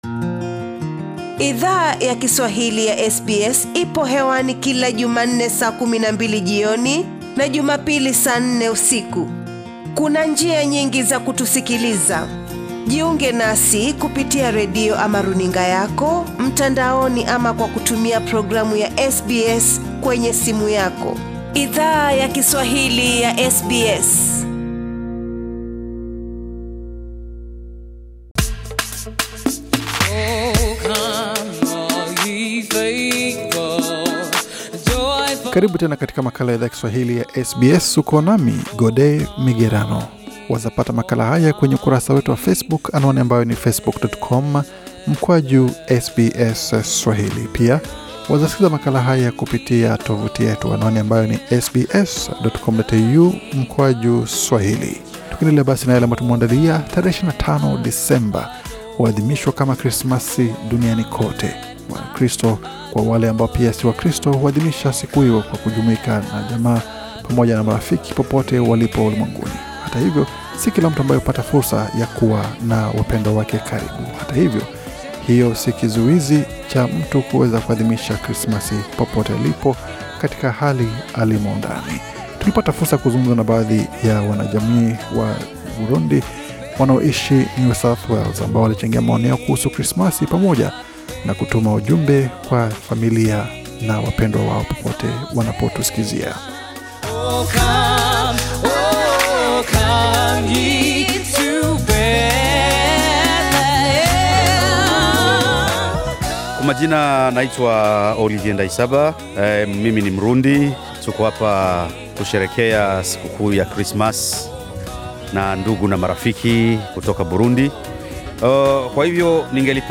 Wanachama wa jamii yawarundi wa NSW, walitathmini yaliyo jiri mwaka huu katika maisha yao binafsi na jamii yao, katika sherehe ya krismasi mjini Sydney, Australia.